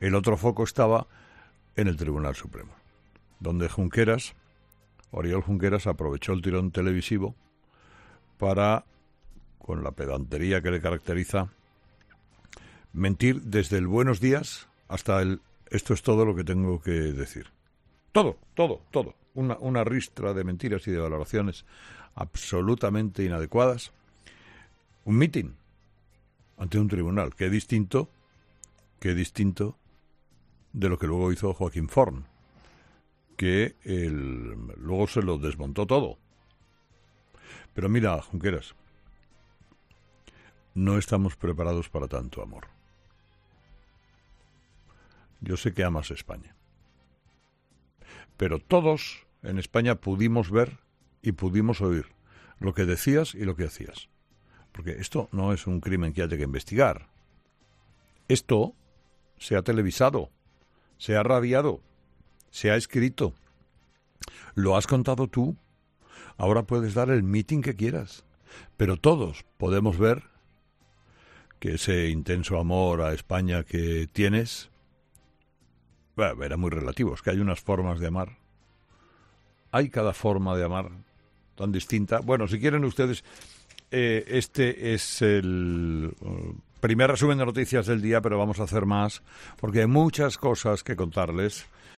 Con esta sutil ironía Carlos Herrera ha respondido al ex vicepresidente de la Generalitat de Cataluña Oriol Junqueras, quien ayer jueves declaró que "ama a España y a las gentes de España".